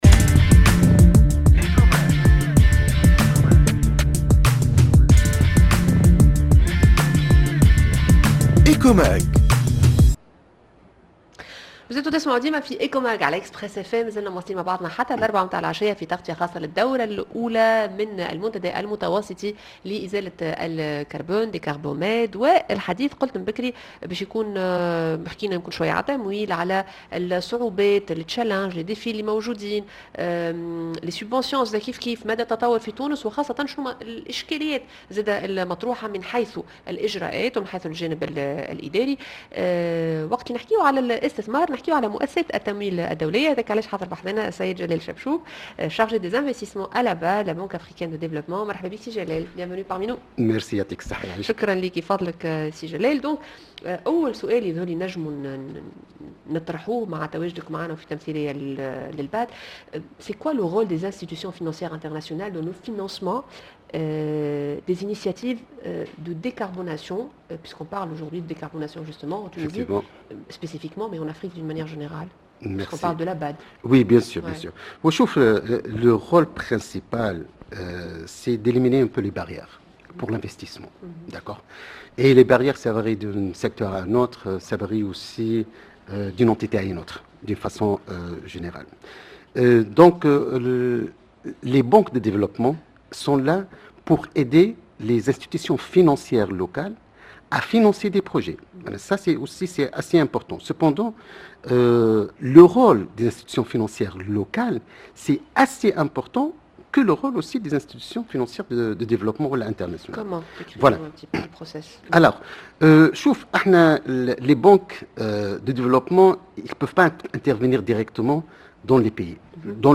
à l'occasion de la première édition de Decarbomed, le forum méditerranéen de la décarbonation